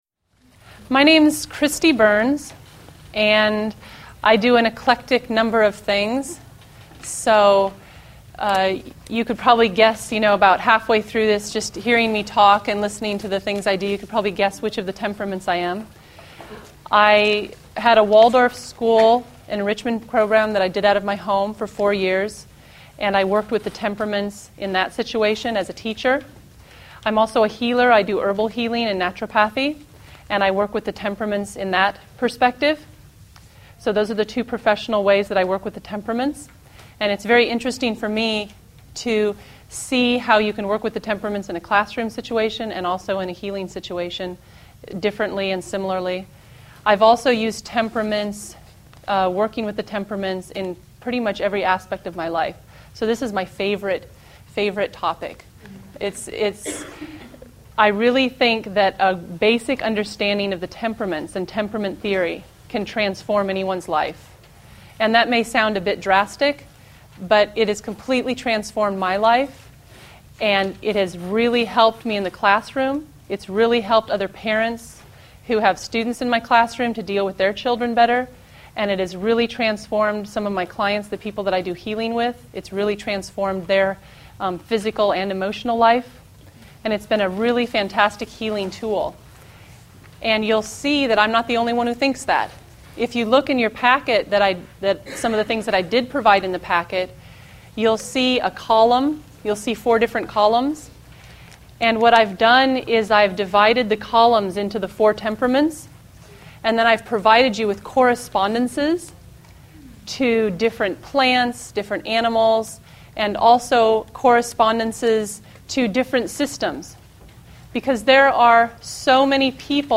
FREE SAMPLE: Comedy & Tragedy: Lecture 1